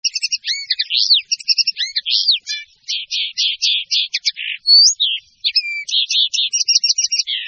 En cliquant ici vous entendrez le chant de la linotte mélodieuse